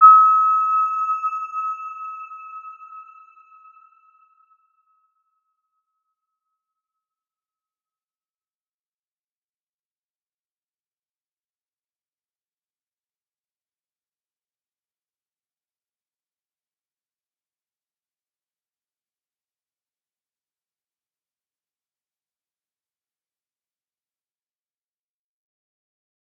Round-Bell-E6-f.wav